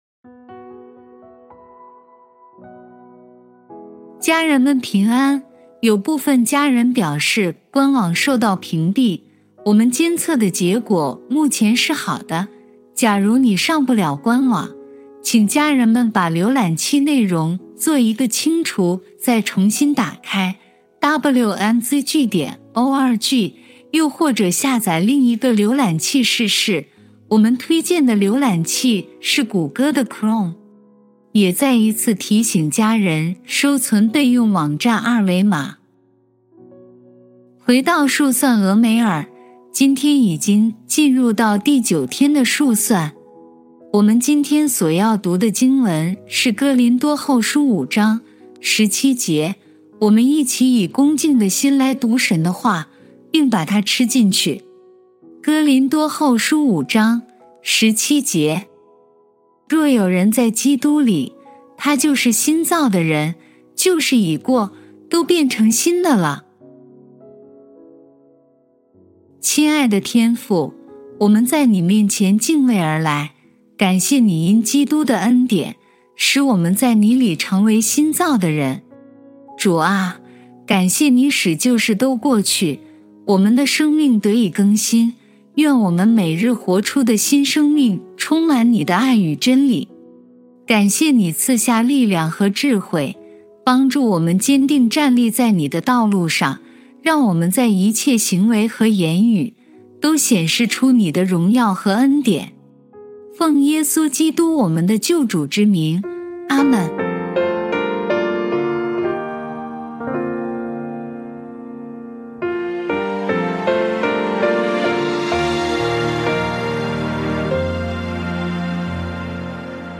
本篇是由微牧之歌撰写、祷告及朗读 数算俄梅珥第九天祷告 更新心志不回头 第一音源 第二音源 「若有人在基…